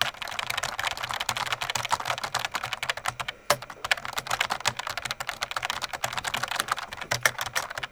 Index of /90_sSampleCDs/AKAI S6000 CD-ROM - Volume 6/Tools/KEYBOARD_TYPING
KEY TYPE 2-S.WAV